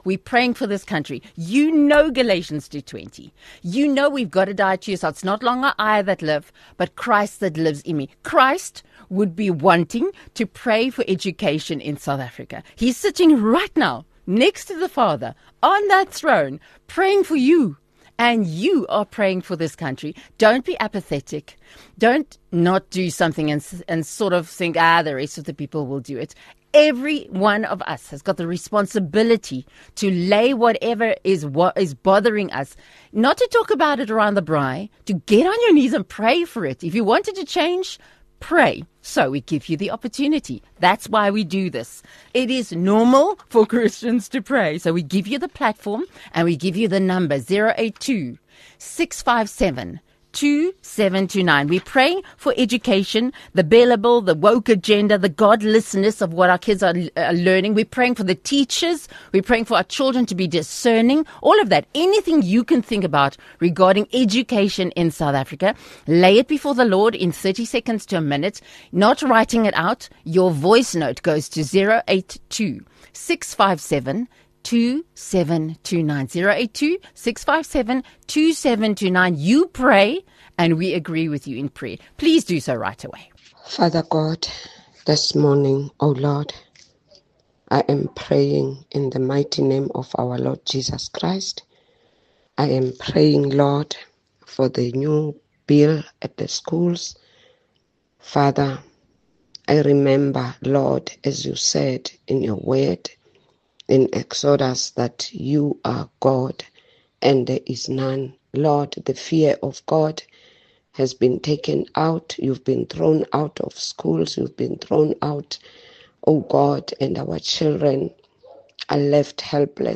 This morning drive programme offers you everything you need to start your day with Good News!